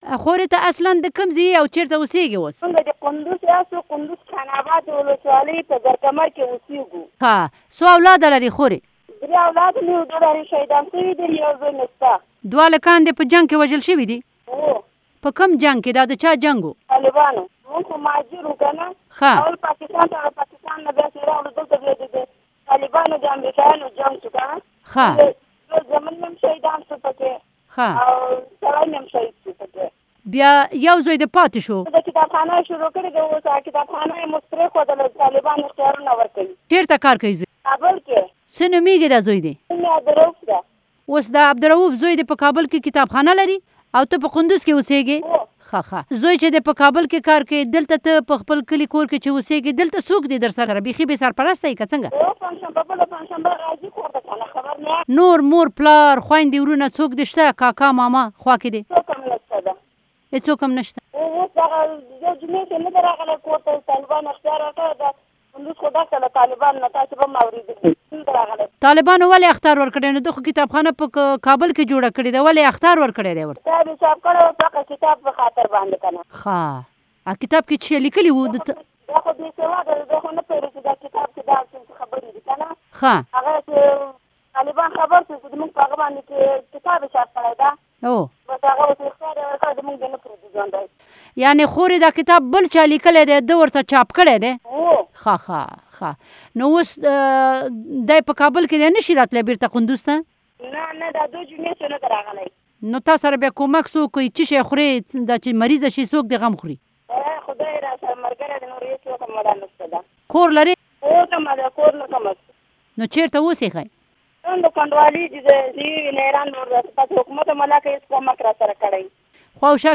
د یوې کونډی سره مرکه